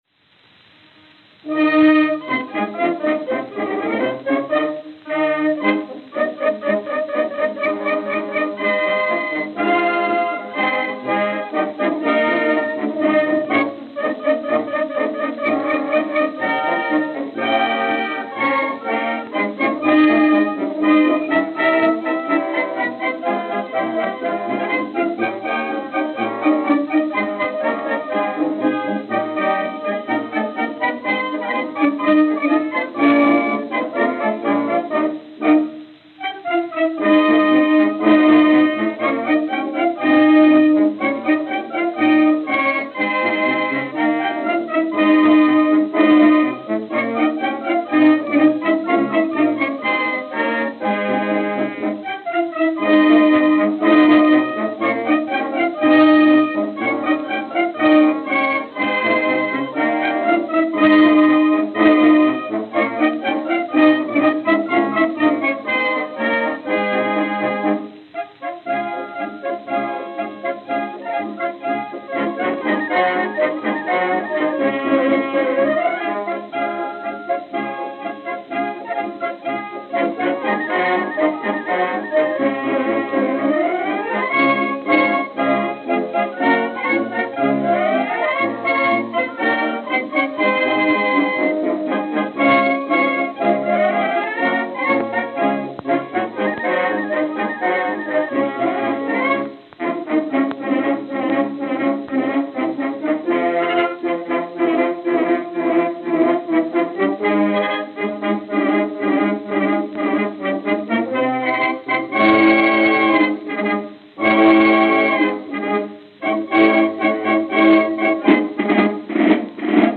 Victor 10-Inch Double-Sided Acoustical Records
Camden, New Jersey. New Office Building Auditorium.